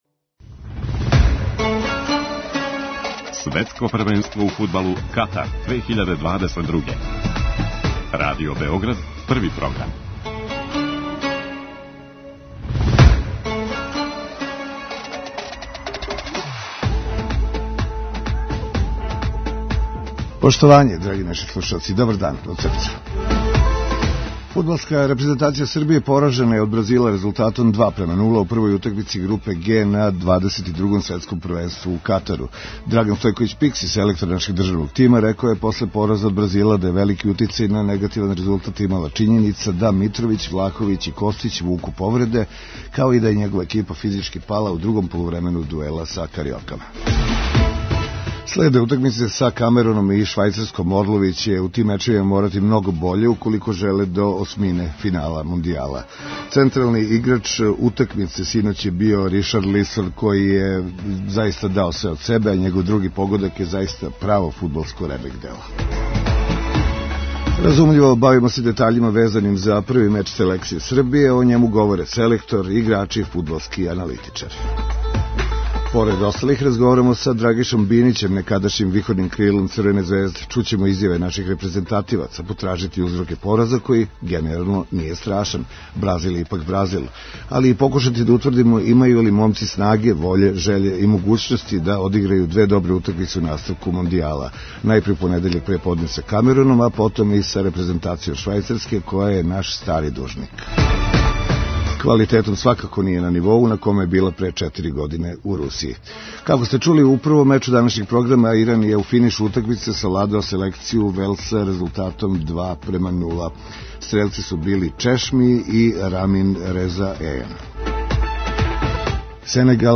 Разумљиво, бавимо се детаљима везаним за први меч селекције Србије, о њему говоре селектор, играчи, фудбалски аналитичари.